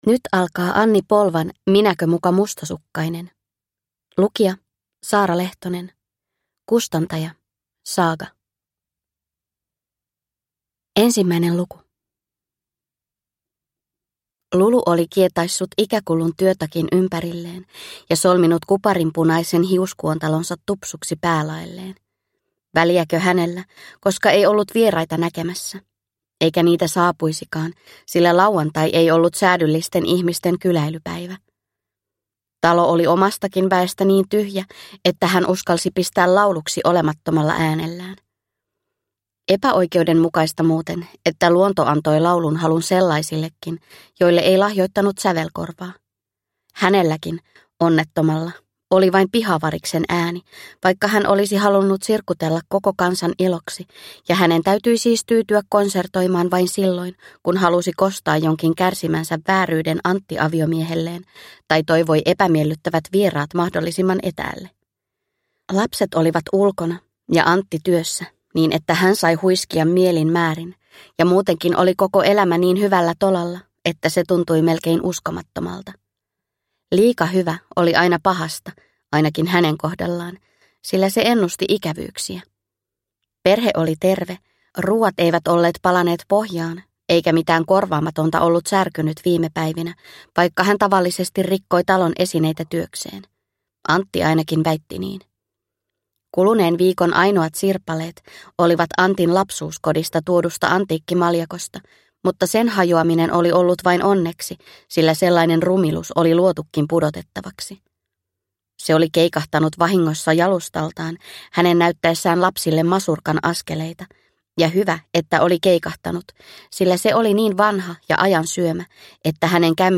Minäkö muka mustasukkainen (ljudbok) av Anni Polva